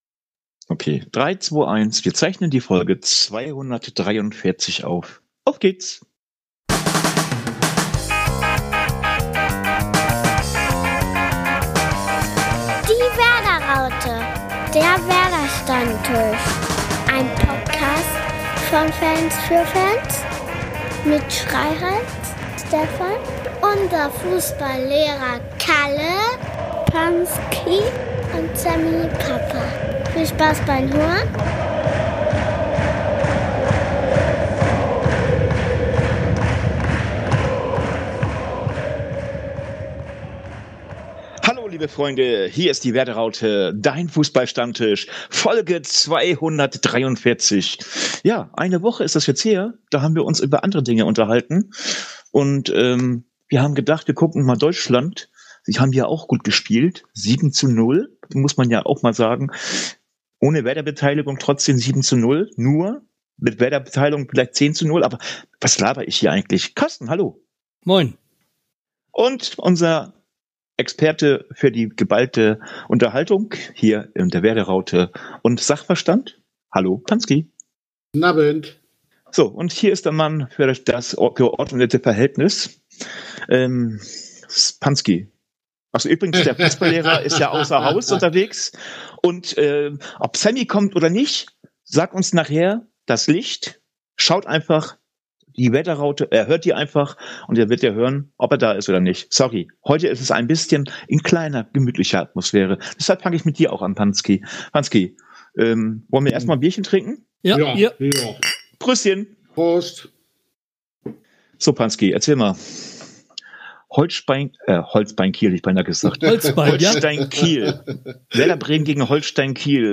Der erste Heimsieg der Saison hat dazu geführt, dass aus dem Quartett am Stammtisch ein Trio geworden ist, das sich nach der Länderspielpause nur schemenhaft an das Spiel erinnern können.